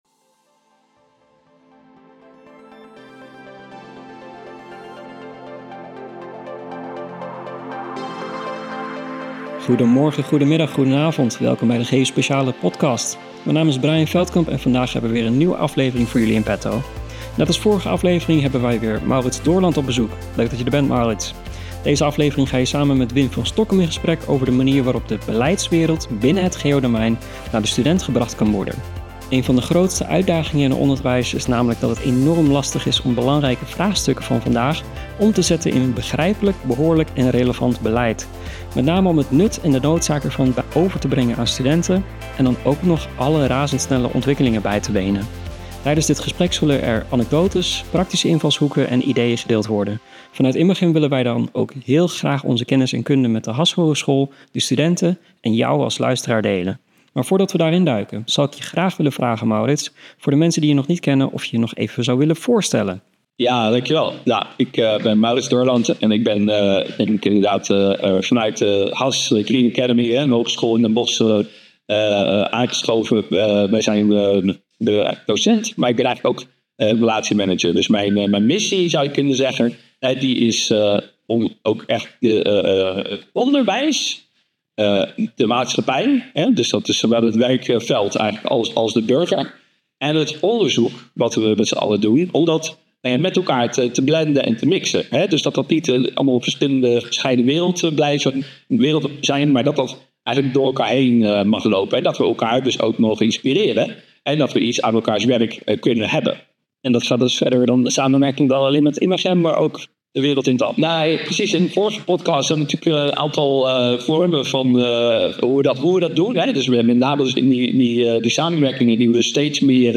Kortom een intrigerende opzet voor een bijzonder gesprek tussen twee bevlogen onderwijzers.